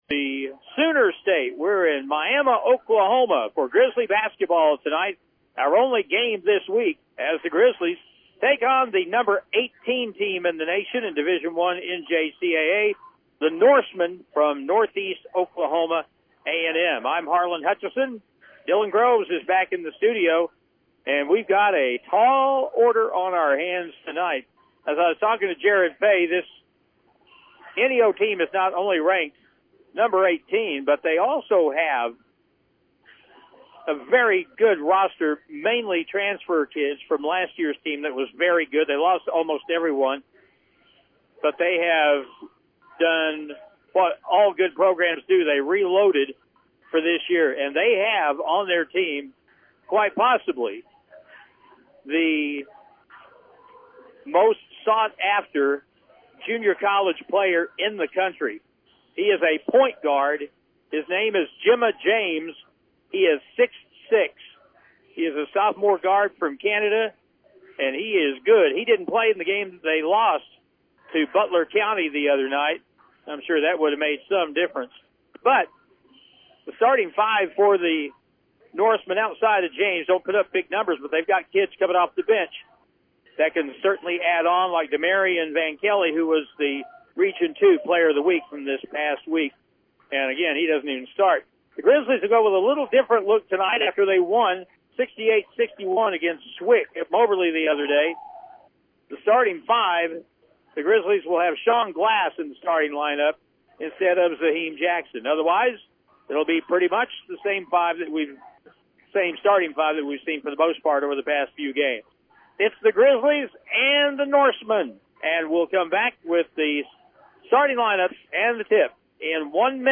Game Audio